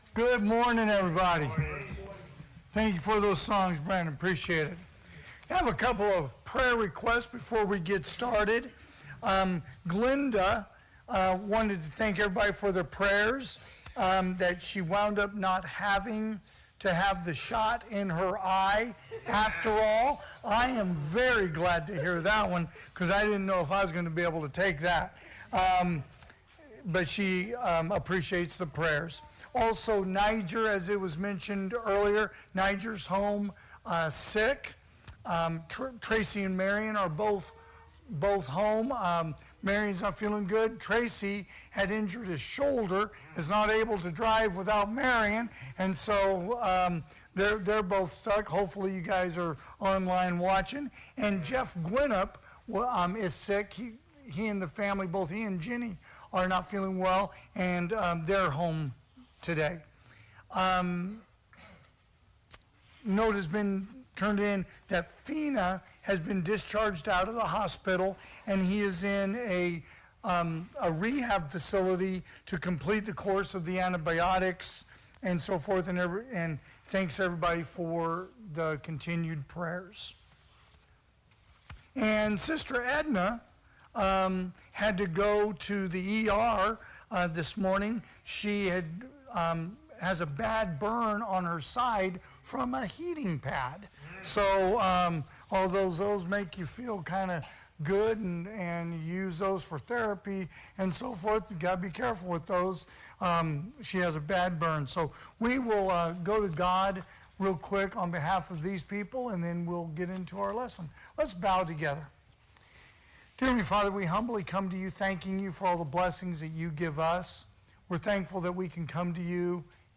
2024 (AM Worship) "Thanksgiving"
Sermons